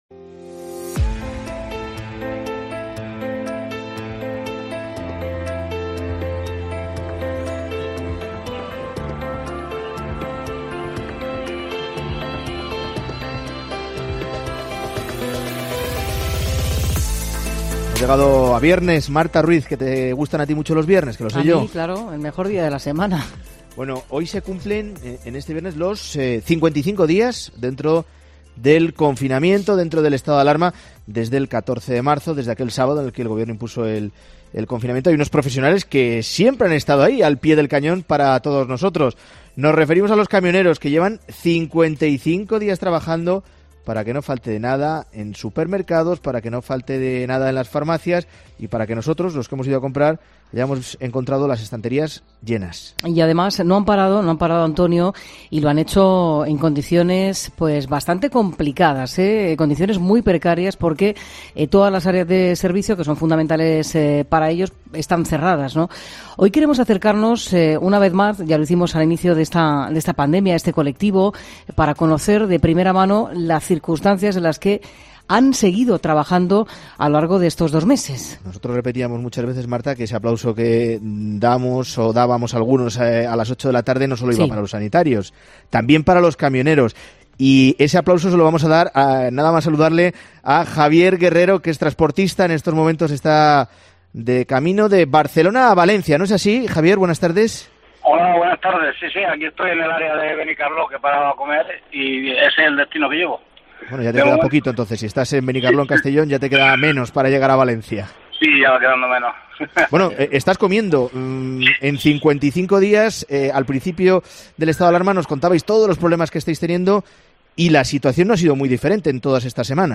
Mediodía COPE Mediodía COPE del de 8 de mayo de 2020 de 13 a 14 Para que no falte nada en supermercados y farmacias, los camioneros llevan 55 días trabajando en condiciones muy precarias porque todas las áreas de servicio están cerradas. Hablamos con dos de ellos.